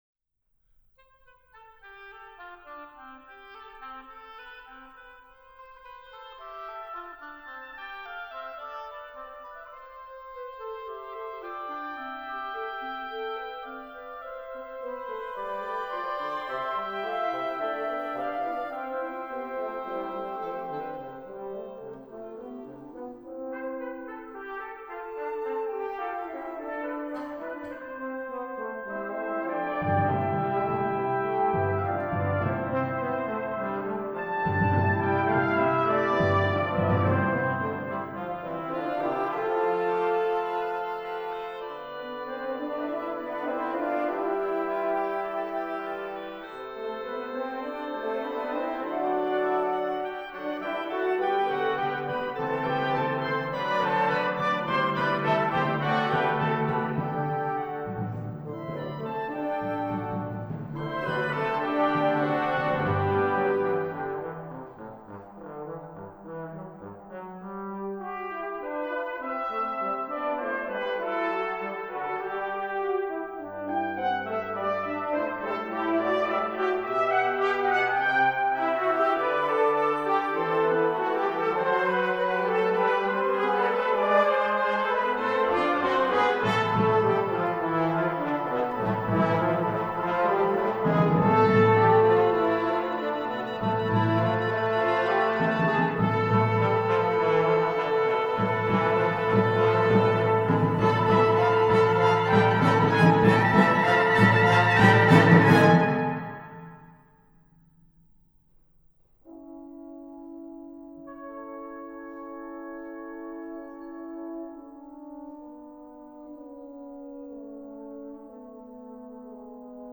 A short invention for wind ensemble based on the compositional techniques of J.S. Bach